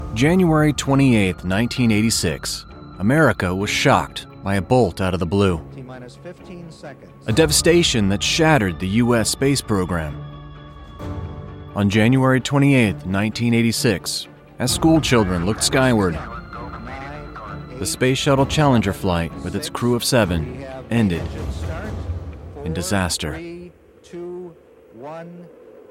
Friendly, Warm, Conversational.
Narration